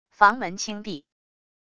房门轻闭wav音频